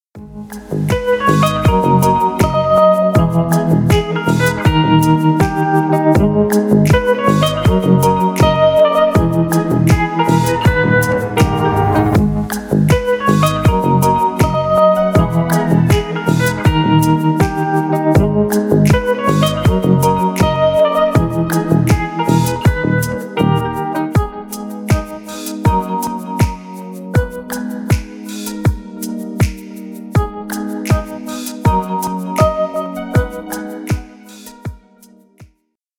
Поп Музыка
без слов